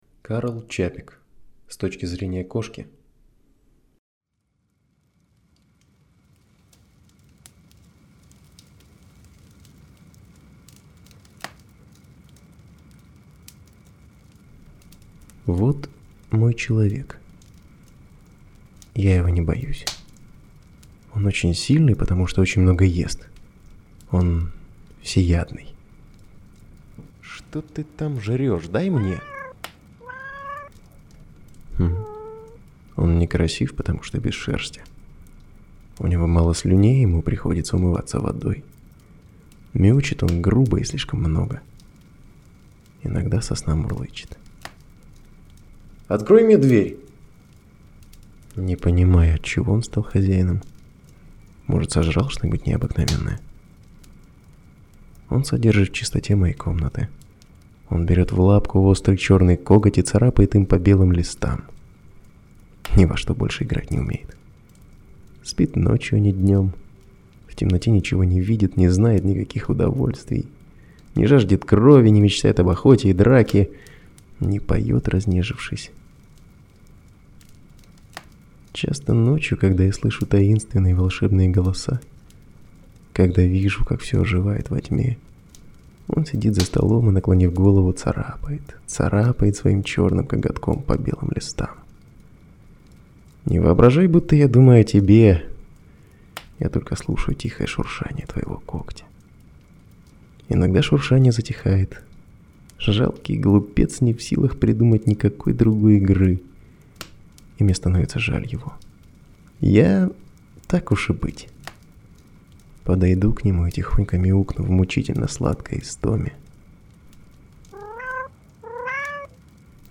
С точки зрения кошки - аудио рассказ Чапека - слушать онлайн